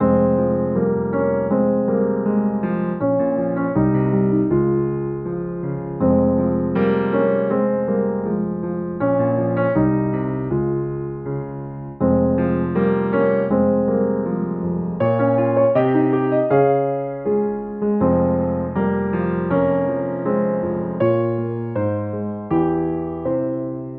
Dark Keys 4 BPM 80.wav